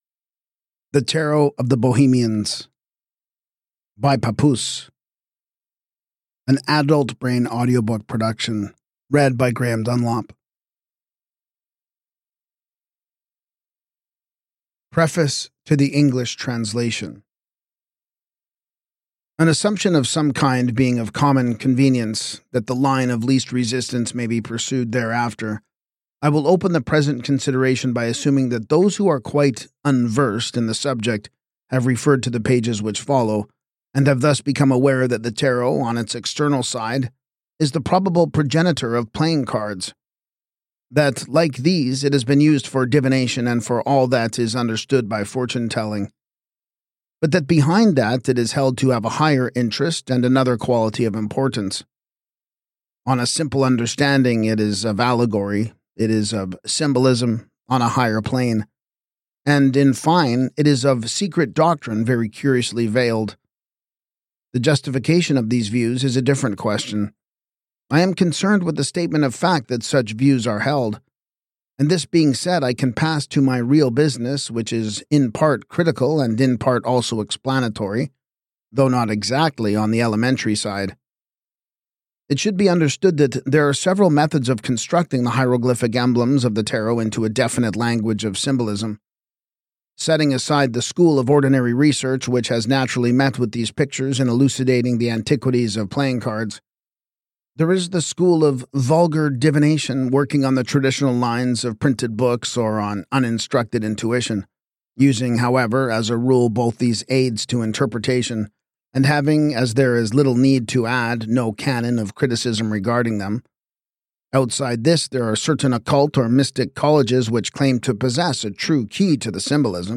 Both a manual of divination and a philosophical treatise, this audiobook guides listeners through the arcana as stages of inner transformation, unlocking the universal principles encoded in the cards.